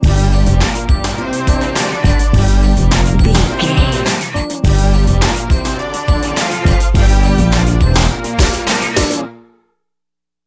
Aeolian/Minor
Fast
drum machine
synthesiser
electric piano
Eurodance